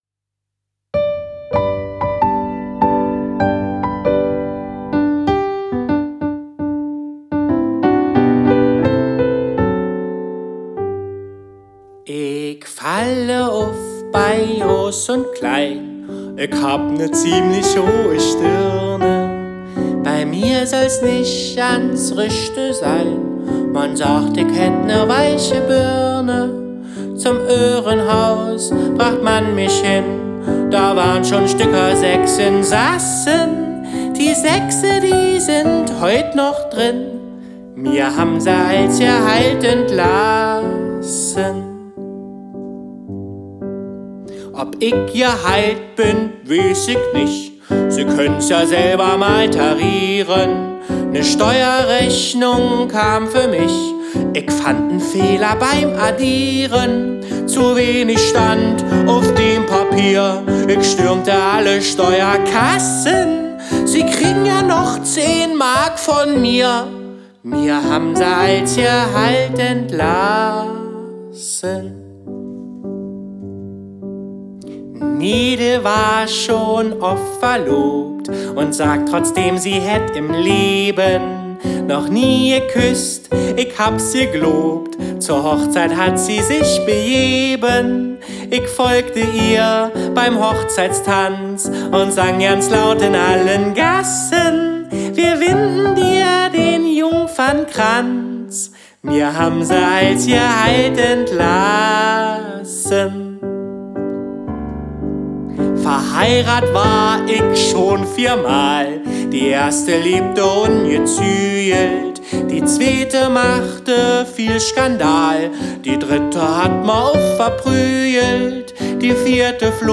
Der Sänger.
Der Pianist.